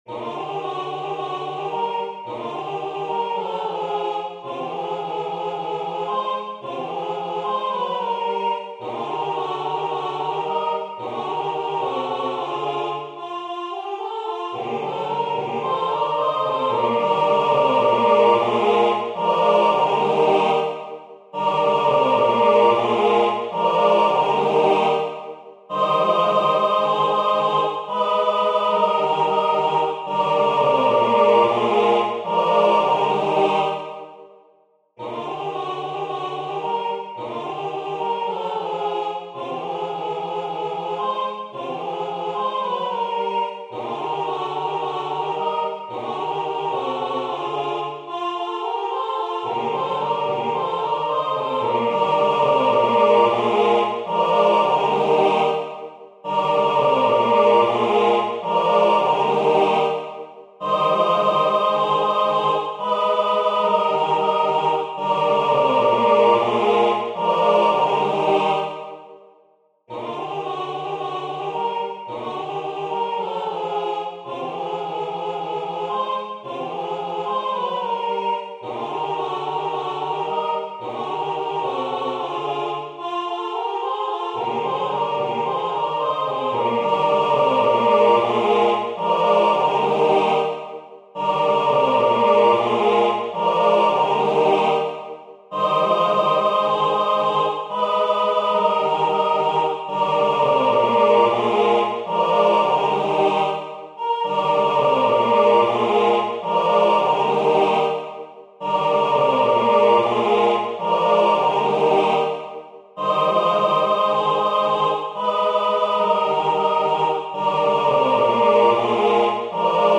CHÓR